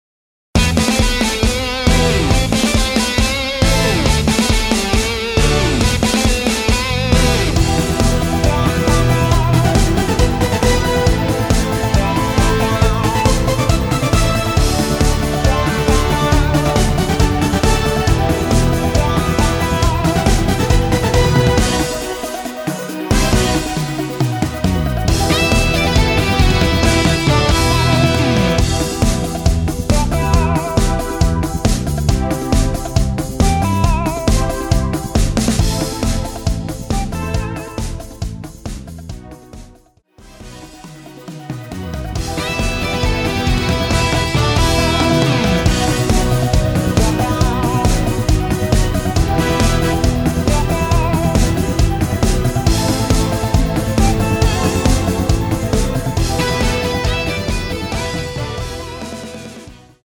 Em
◈ 곡명 옆 (-1)은 반음 내림, (+1)은 반음 올림 입니다.
앞부분30초, 뒷부분30초씩 편집해서 올려 드리고 있습니다.